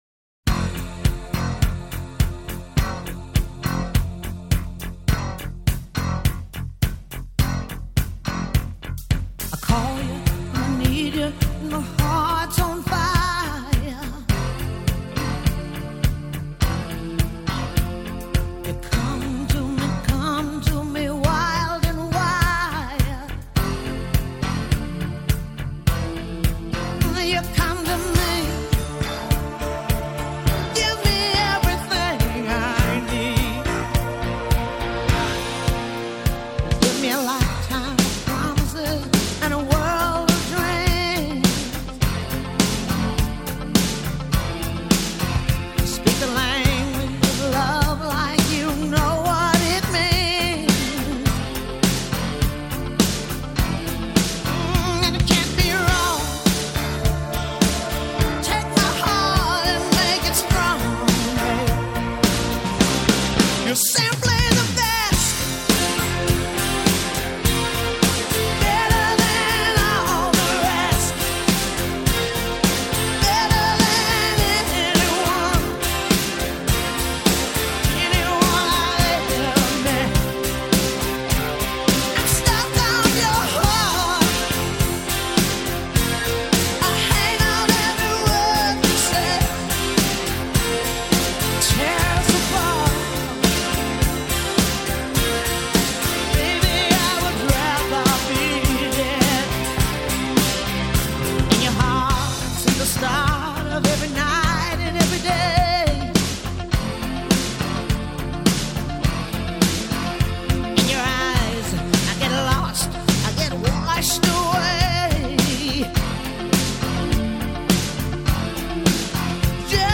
Жанр: rnb